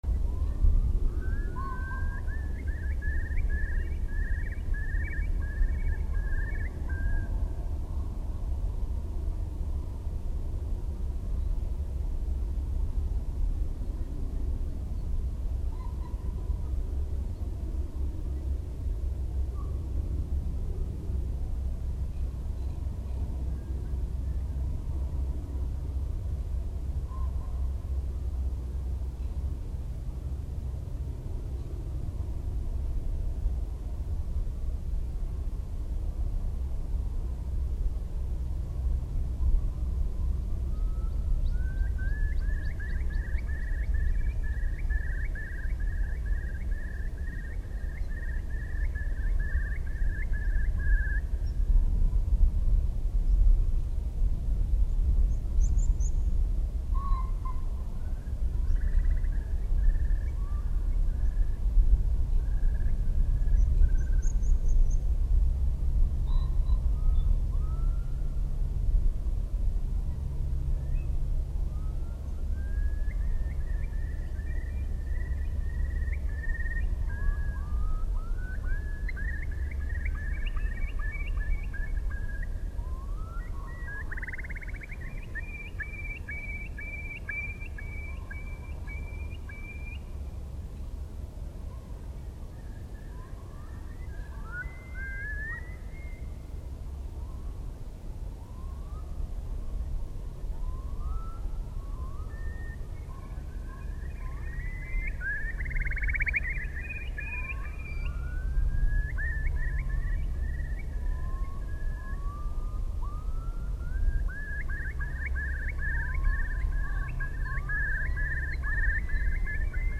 This recording was done in Highland Perthshire, also in March, using the same equipment, but with a rycote windjammer and the microphones used as boundary layer microphones, because of the wind.
It felt very relaxed on the hilltop with the typical sounds of Scottish Birds.
OKM Highland Perthshire.MP3